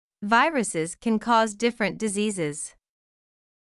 ※当メディアは、別途記載のない限りアメリカ英語の発音を基本としています。
初級/z/の発音